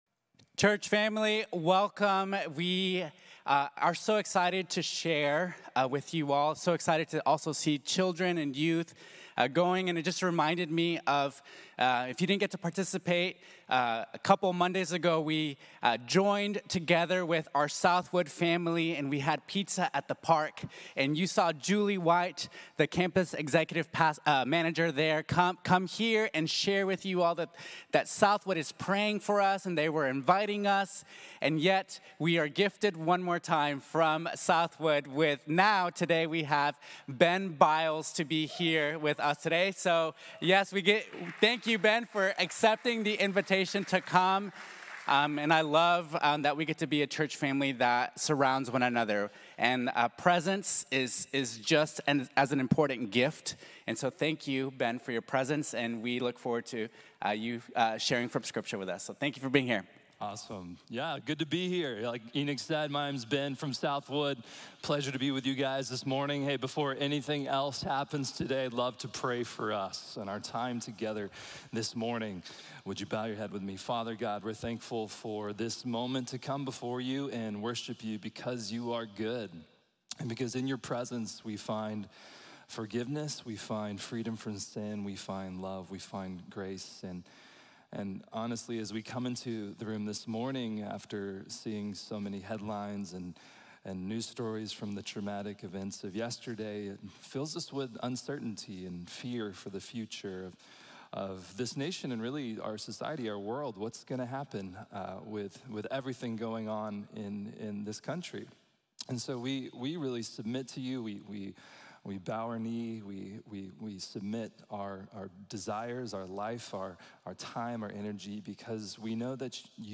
The Overwhelming Love of God | Sermon | Grace Bible Church